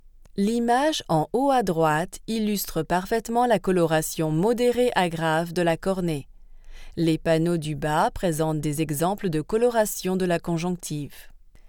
Native French Voice Over artist.
Professional home studio, based in New York. 15+ years experience.
Sprechprobe: Industrie (Muttersprache):